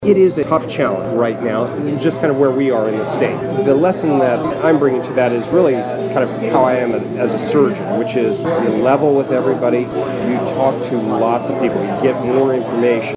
Below are some of the soundbites from the media sit down.